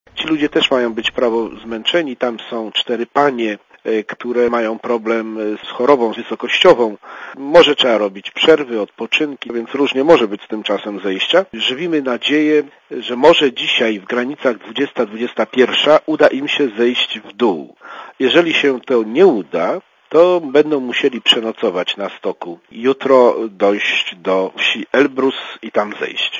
Dla Radia Zet mówi konsul Klimański (103 KB)